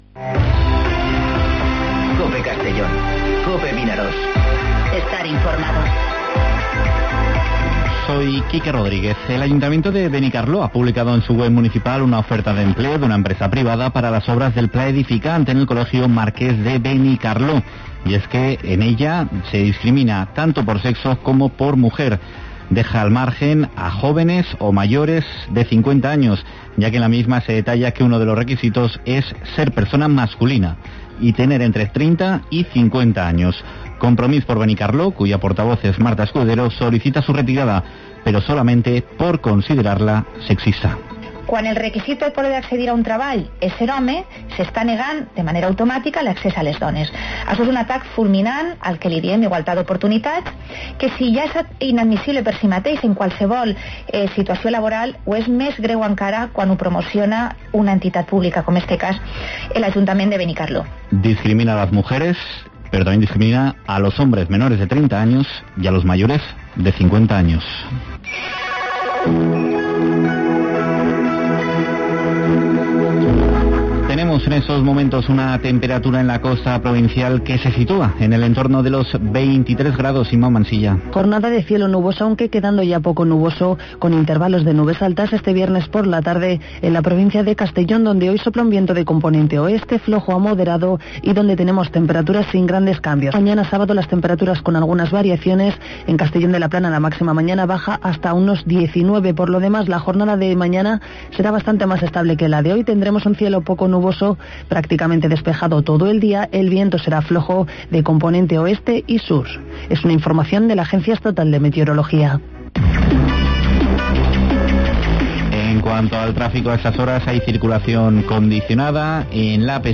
Informativo Mediodía COPE en Castellón (29/11/2019)